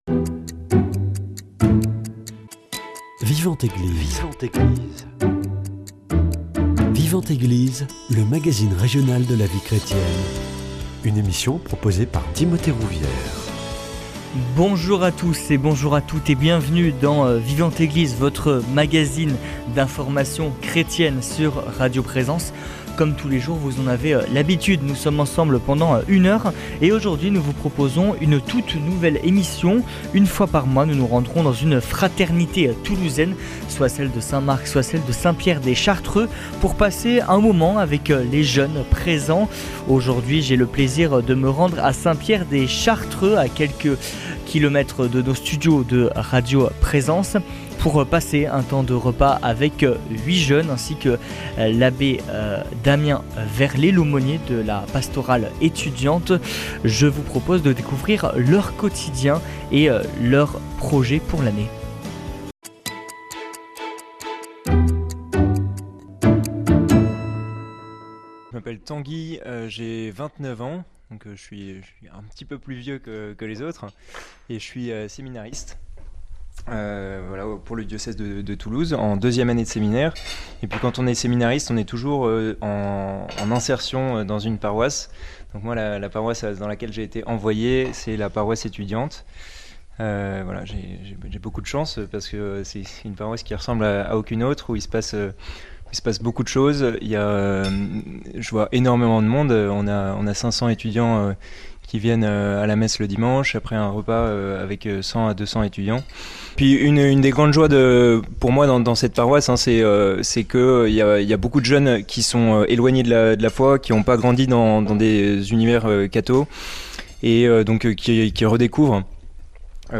Nous avons partagé un repas avec les jeunes de la fraternité Saint-Pierre des Chartreux à Toulouse.